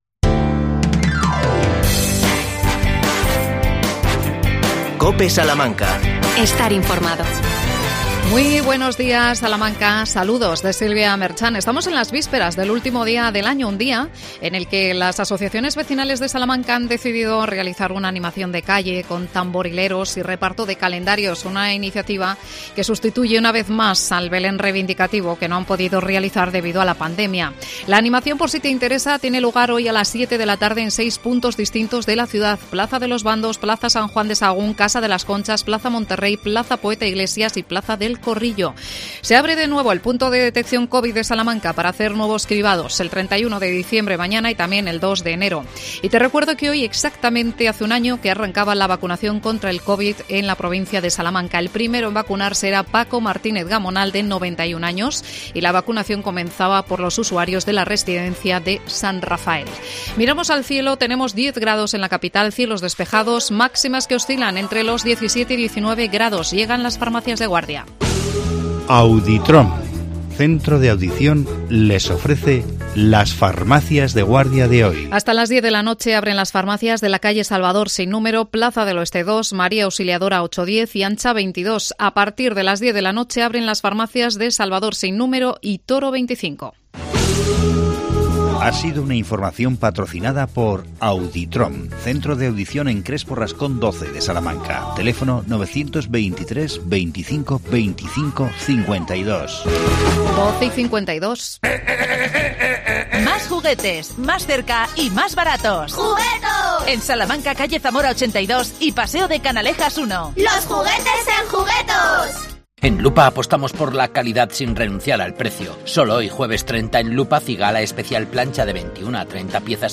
AUDIO: Casas rurales, alternativa para el Fin de Año. Entrevistamos